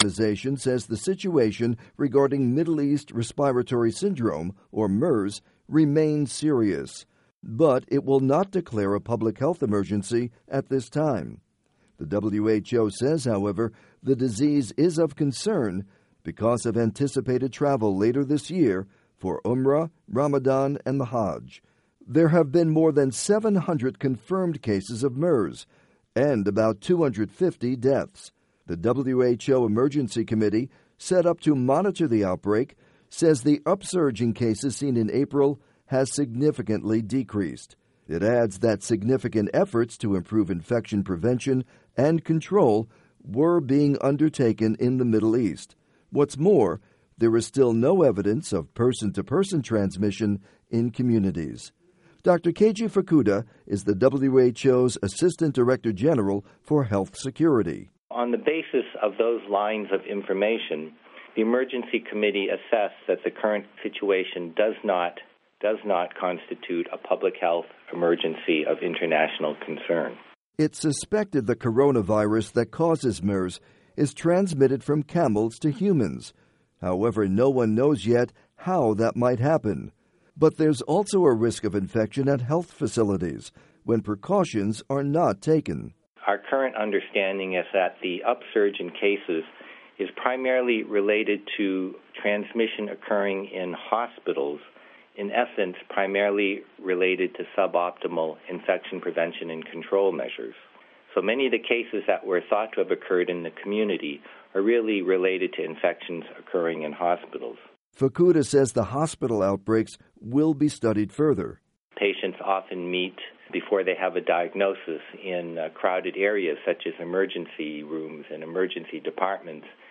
report on MERS